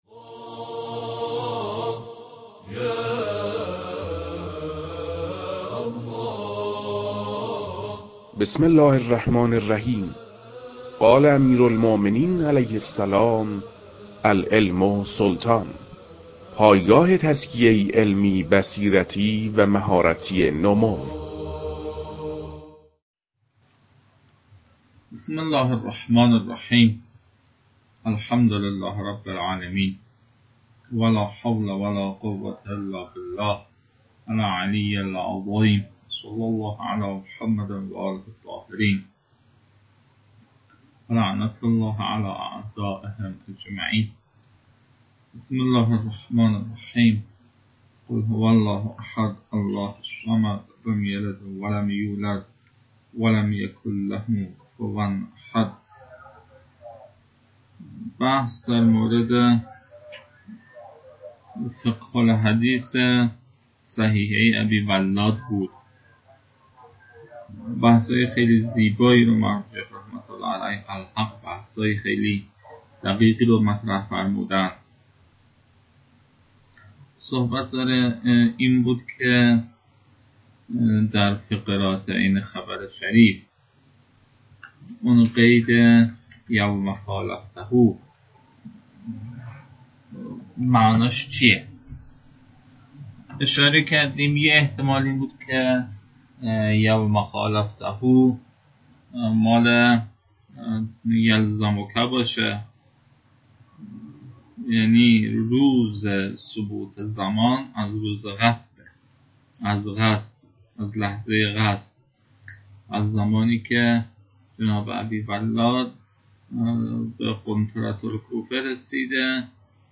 عمده این جلسه به متن‌خوانی جلسه سابق گذشت.